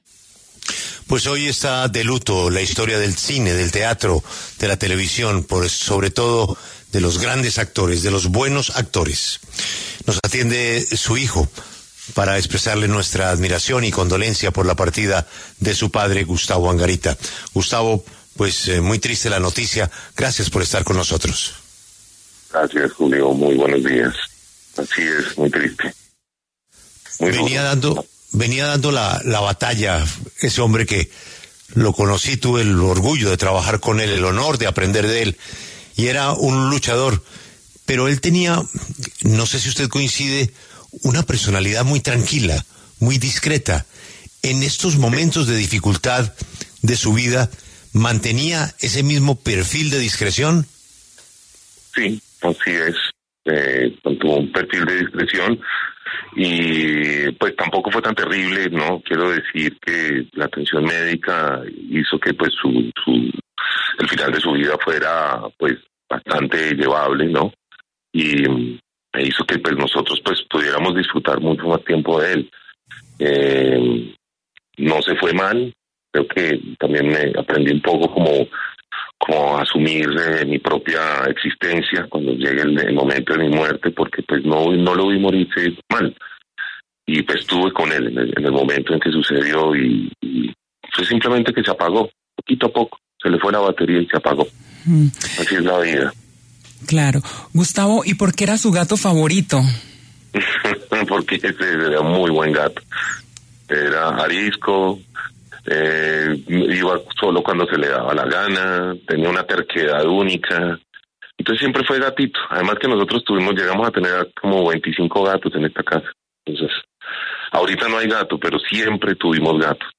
Tema del DíaEspeciales